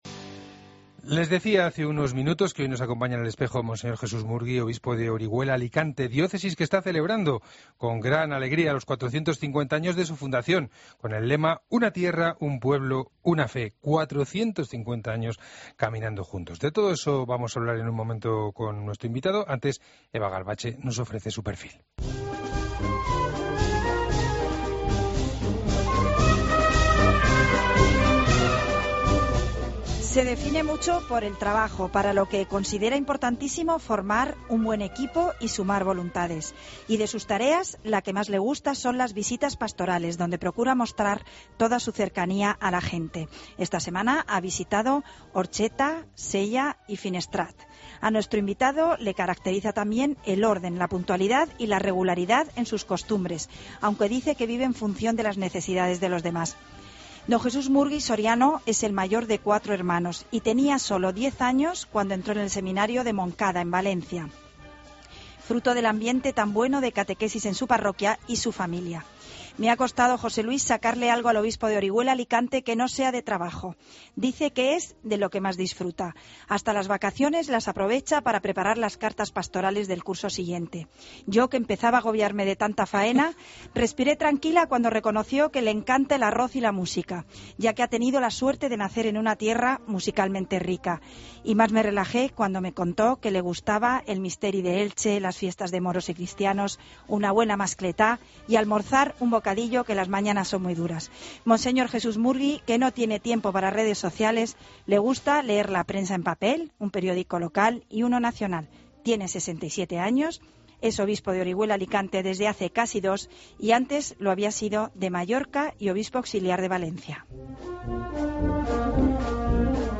Escucha la entrevista completa a monseñor Jesús Murgui Soriano en 'El Espejo'